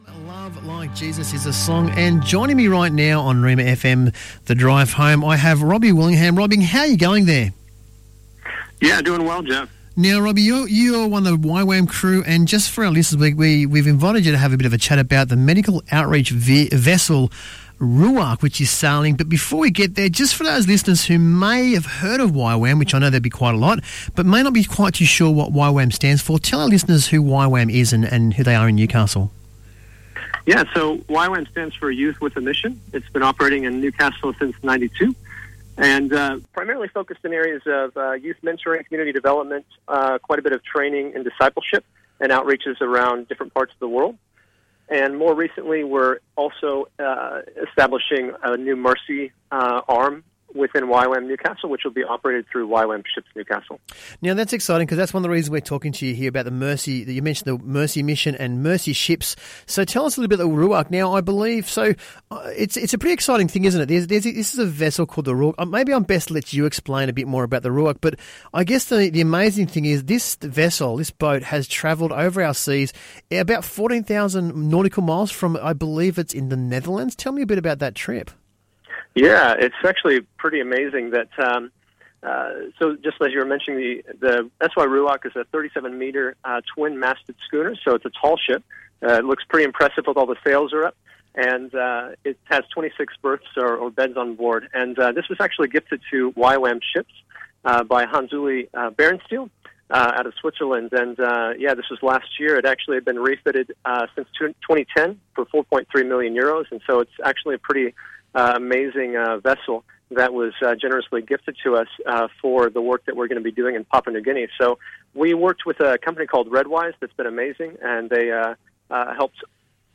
Rhema Newcastle 99.7 FM - Live Interview | YWAM Anchor of Hope